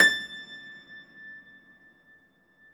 53a-pno21-A4.wav